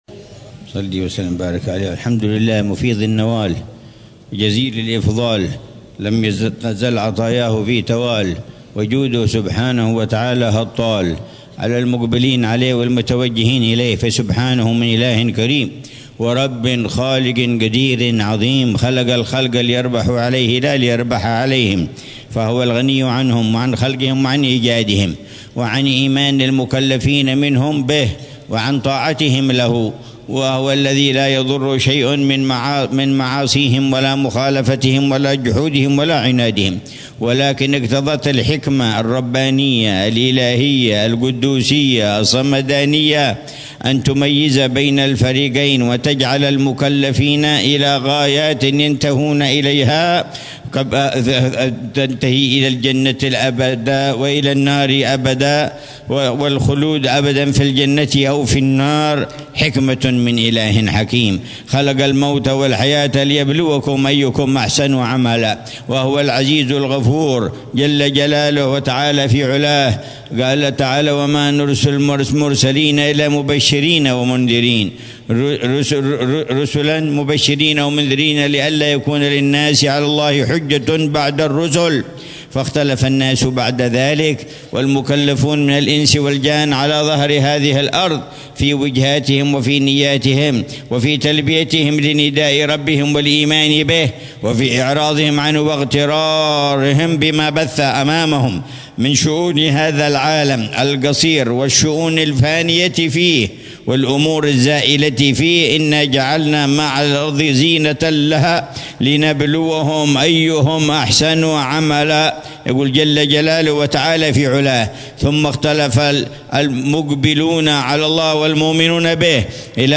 كلمة الحبيب عمر بن حفيظ ليلة ذكرى المولد النبوي الشريف 1447هـ في مصلى أهل الكساء بدار المصطفى ليلة الخميس 12 ربيع الأول 1447هـ